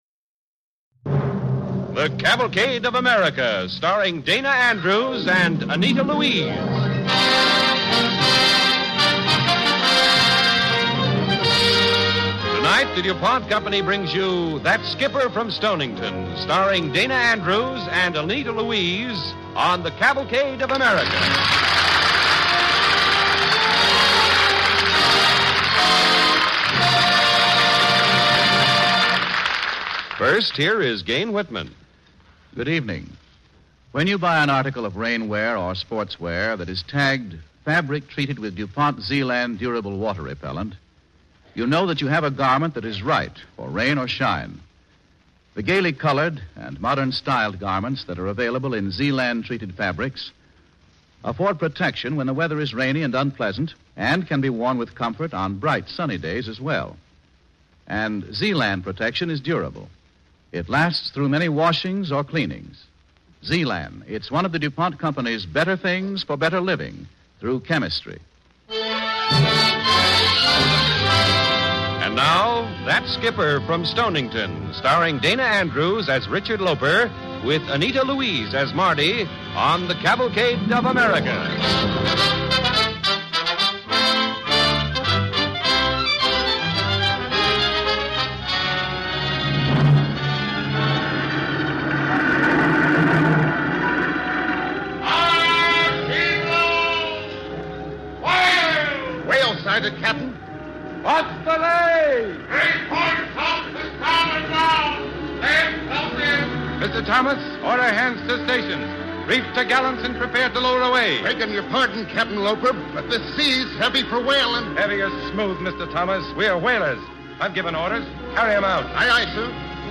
starring Dana Andrews and Anita Louise
Cavalcade of America Radio Program